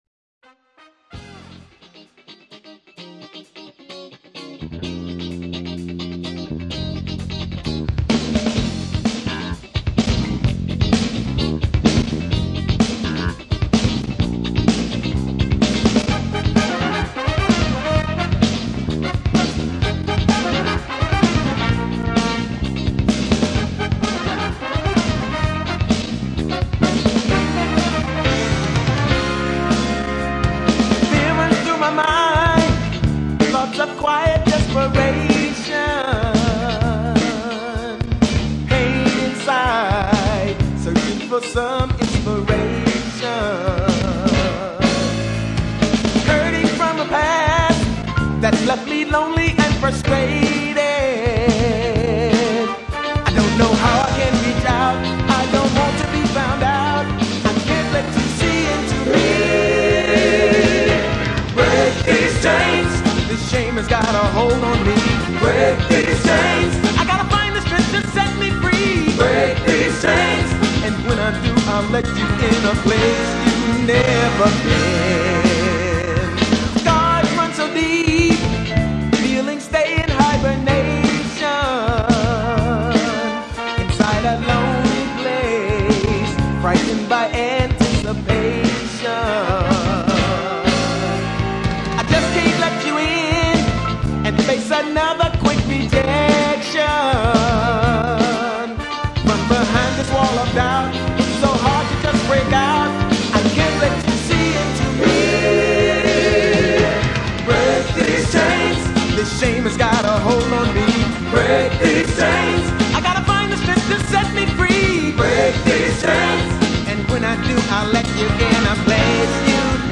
Another great sax solo